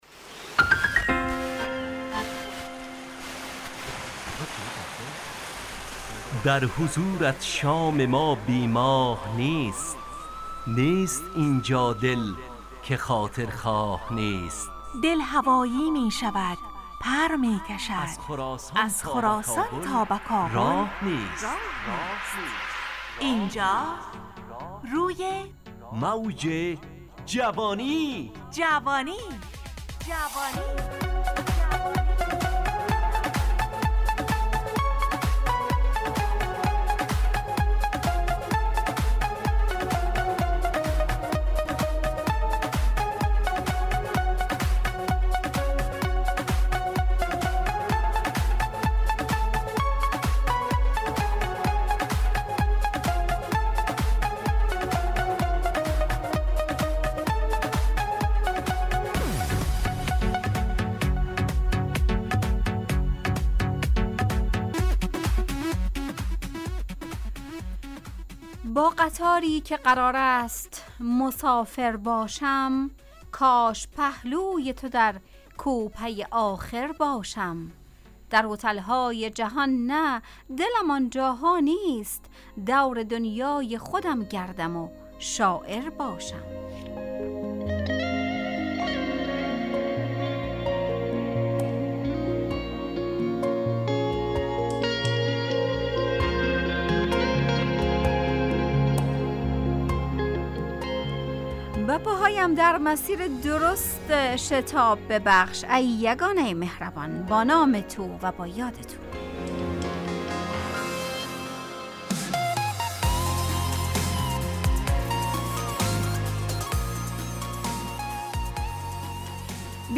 روی موج جوانی، برنامه شادو عصرانه رادیودری.
همراه با ترانه و موسیقی مدت برنامه 55 دقیقه . بحث محوری این هفته (شتاب و عجله ) تهیه کننده